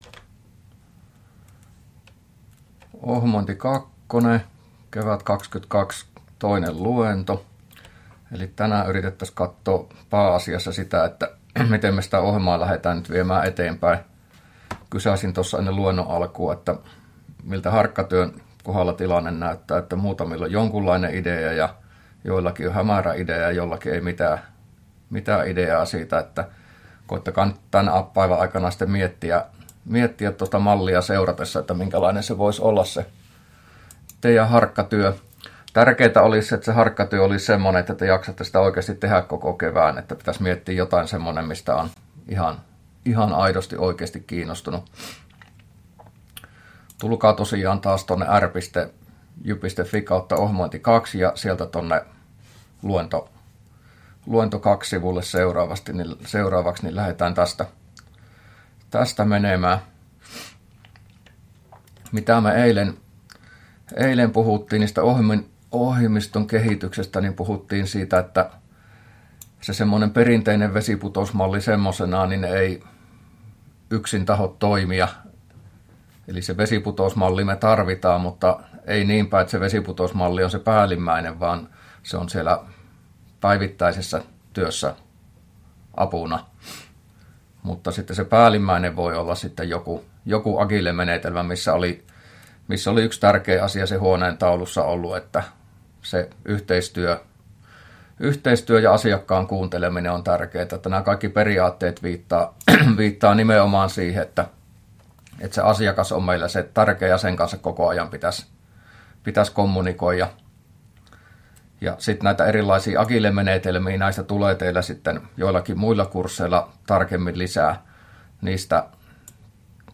luento02a